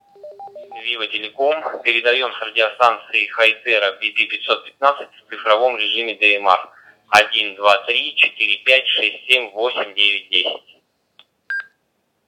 Модуляция в цифровом режиме DMR:
bp-515-tx-digital.wav